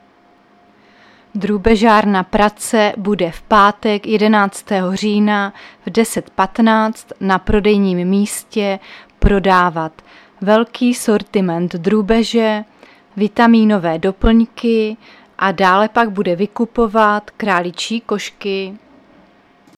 Záznam hlášení místního rozhlasu 9.10.2024
Zařazení: Rozhlas